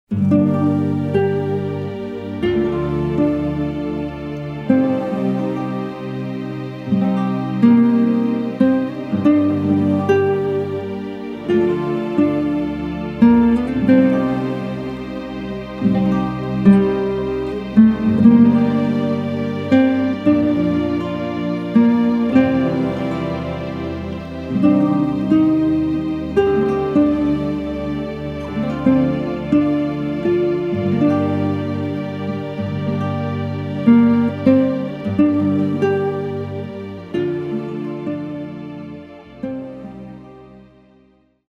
wordless female soprano voice
strong South American influence with pan pipes
ominous sounding tolling church bell